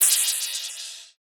Crashes & Cymbals
Boomin - Cymbal 3.wav